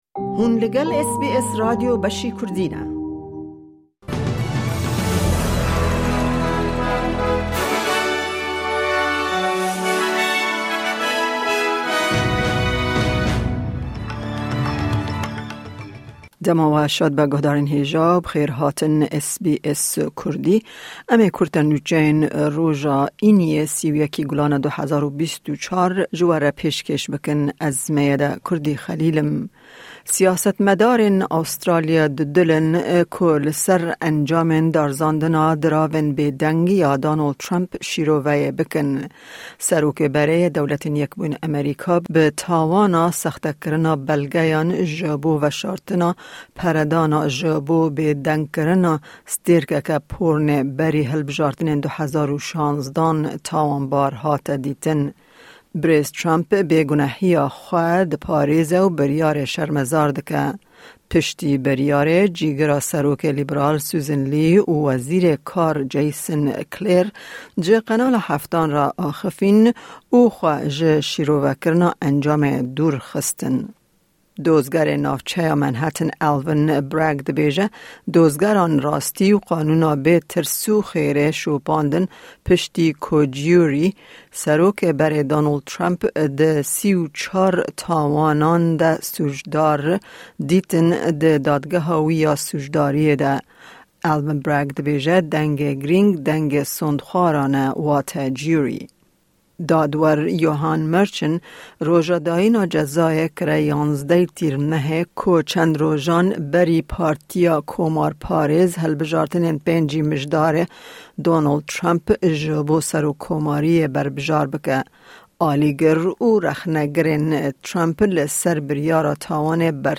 Kurte Nûçeyên roja Înî 31î Gulana 2024